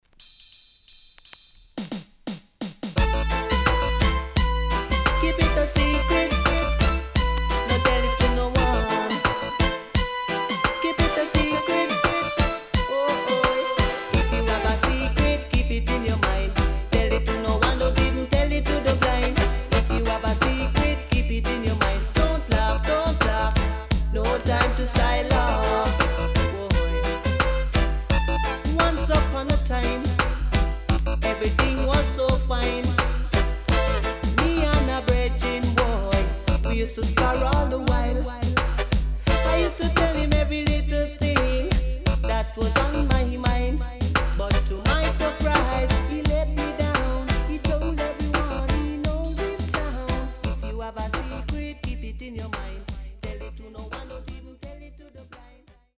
SIDE B:所々チリノイズ入りますが良好です。